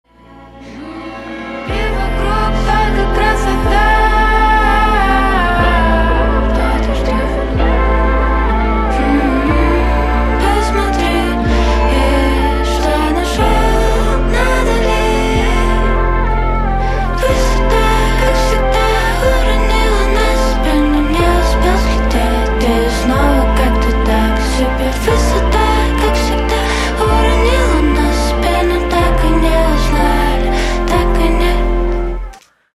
Спокойные И Тихие Рингтоны
Поп Рингтоны